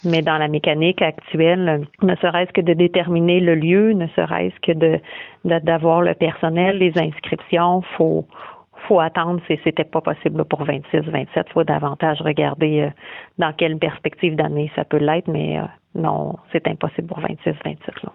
en entrevue.